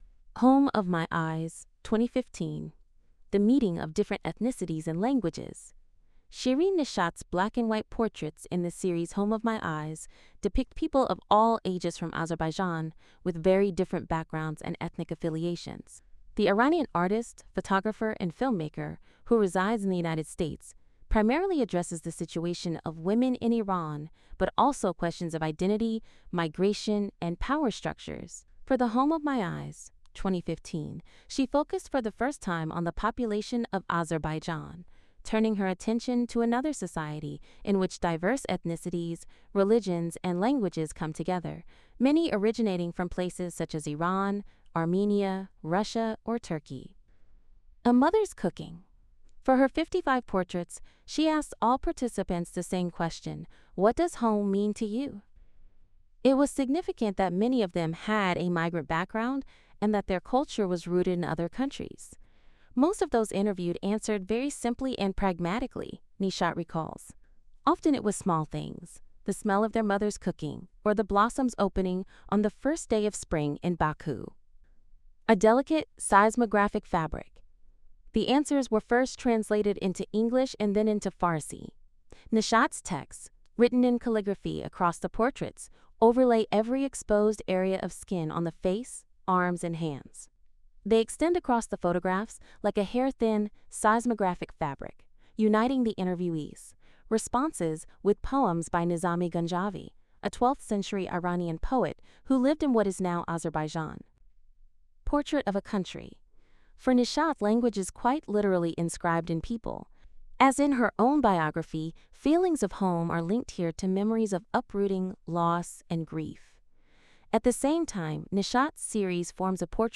Note: The audio transcription is voiced by an AI.